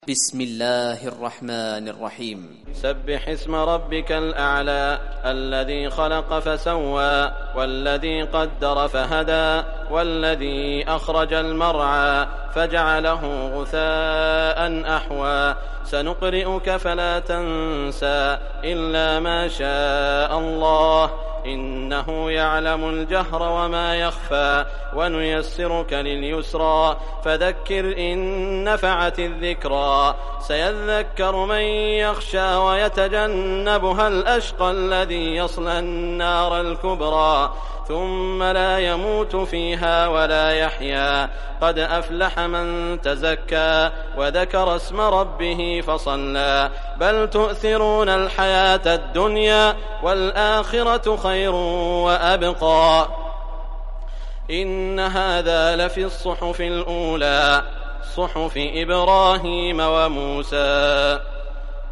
Surah al Ala Recitation by Sheikh Shuraim
Surah al Ala, listen or play online mp3 tilawat / recitation in Arabic in the beautiful voice of Sheikh Saud al Shuraim.